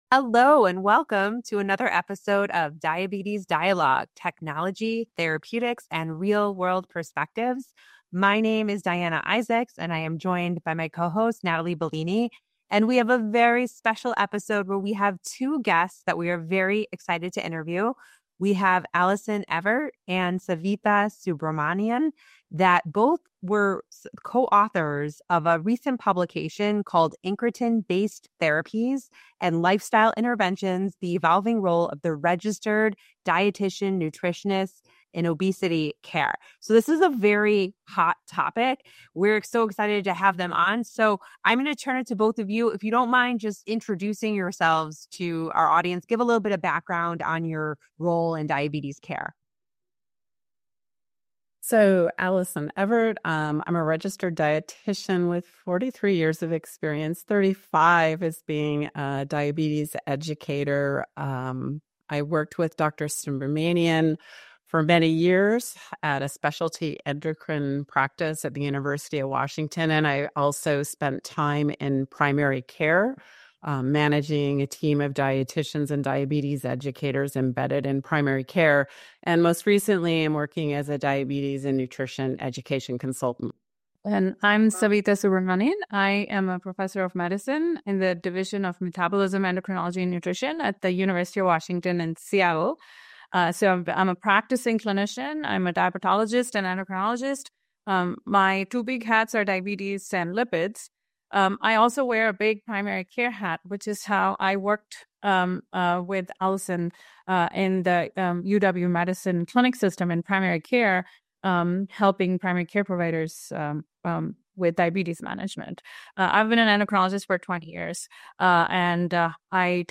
Diabetes Dialogue